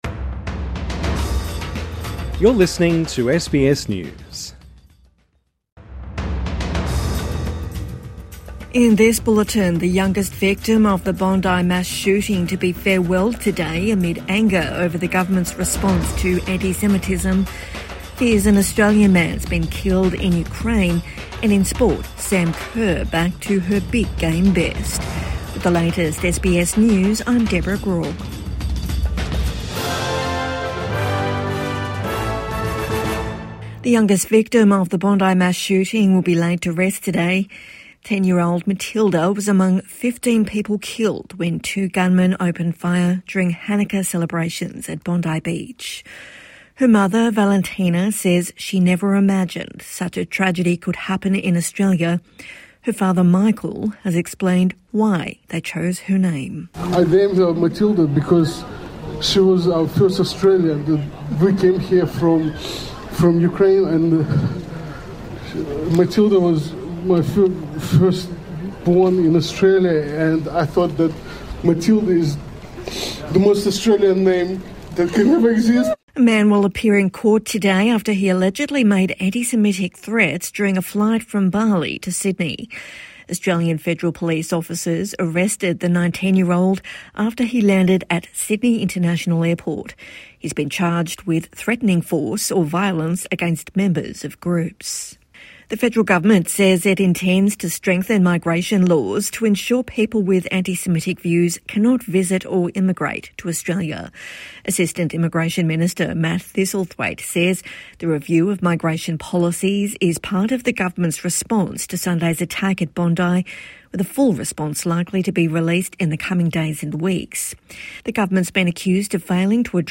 Funeral for youngest victim of Bondi mass shooting | Midday News Bulletin 18 December 2025